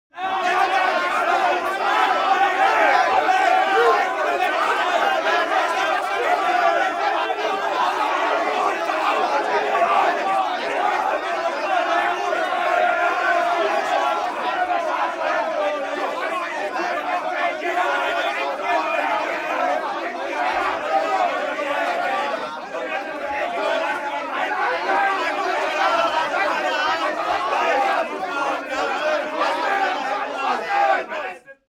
angry_male_crowd_ambience.wav